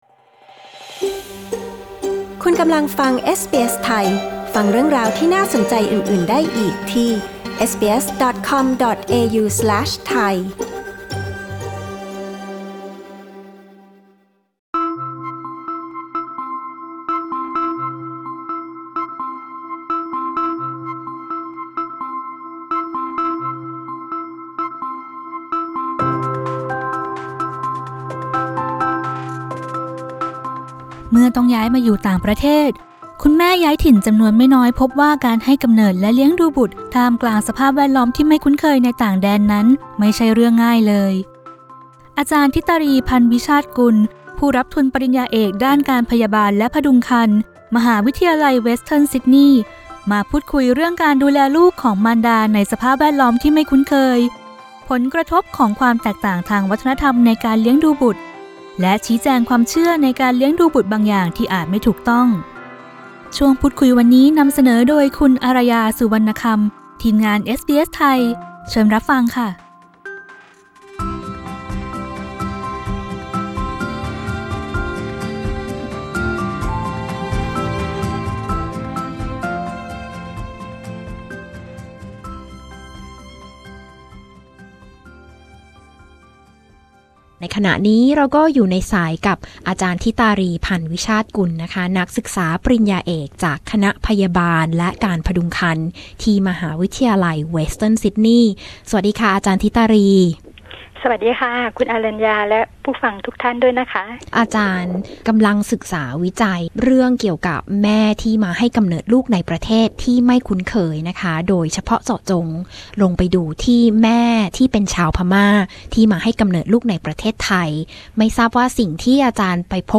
บทสัมภาษณ์ออกอากาศครั้งแรกเมื่อวันที่ 15 พฤศจิกายน 2016